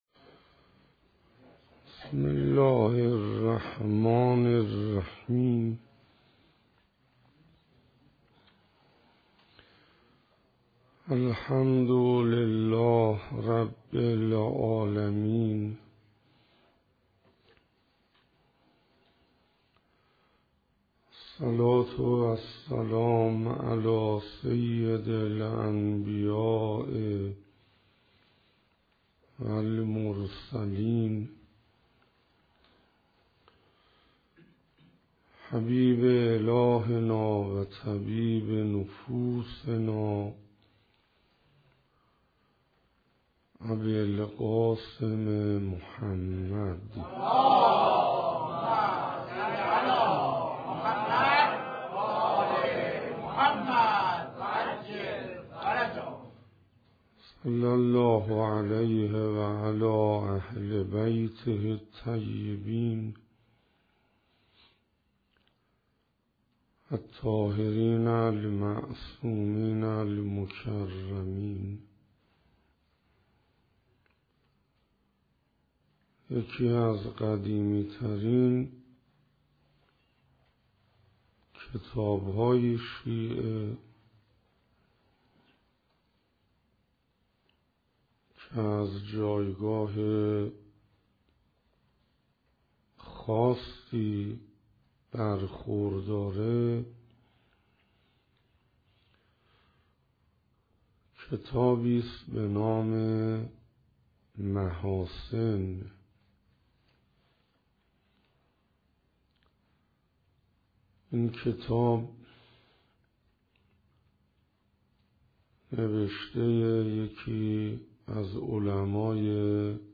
تجارت و خسارت - شب ششم - جمادی الاول 1436 - هیئت حضرت علی اصغر -